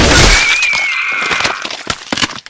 pop.ogg